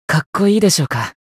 觉醒语音 かっこいいでしょうか？